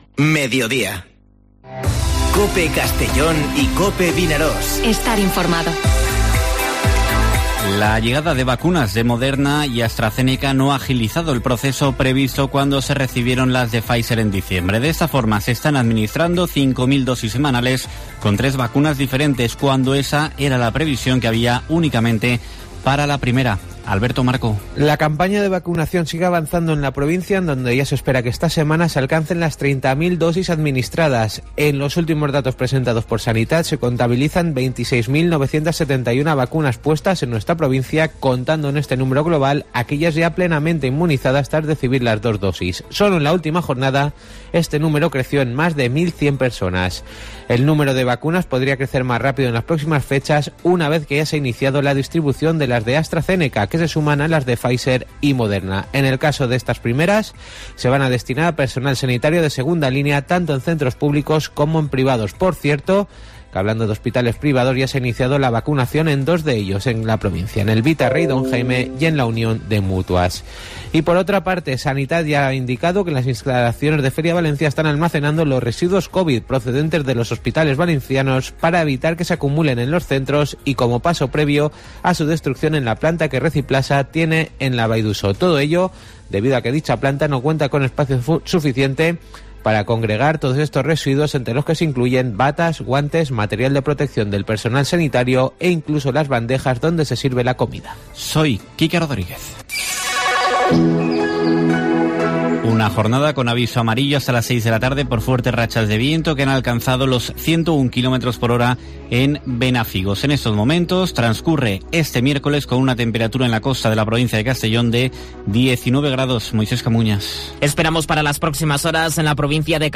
Informativo Mediodía COPE en la provincia de Castellón (10/02/2021)